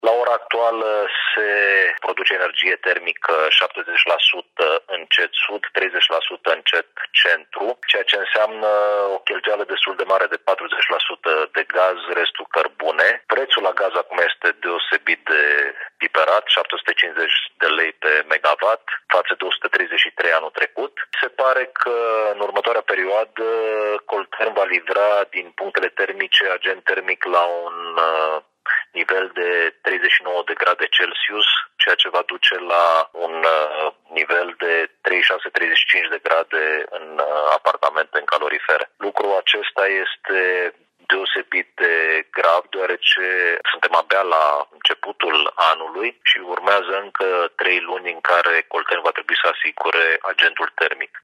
În următoarea perioadă, Colterm va livra agent din punctele termice la o temperatură redusă la 39 de grade, față de circa 60 de grade, cât ar fi normal, mai spune Ovidiu Drăgănescu.